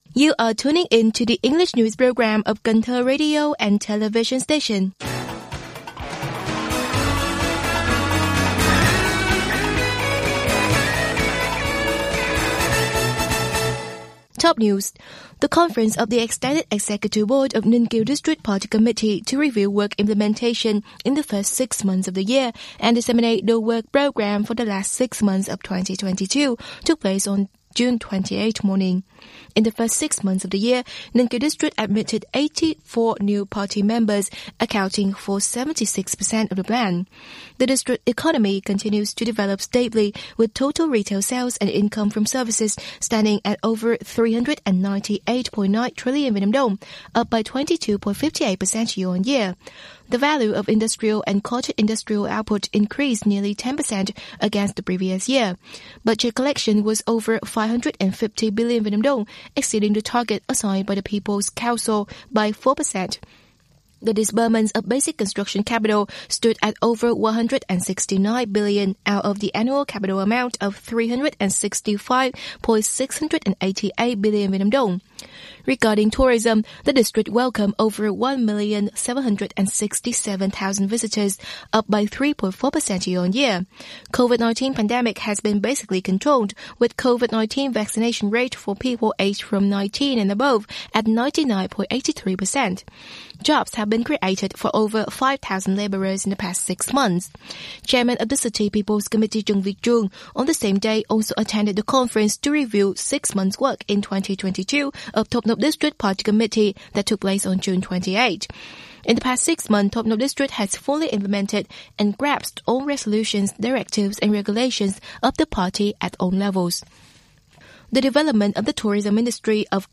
Bản tin tiếng Anh 28/6/2022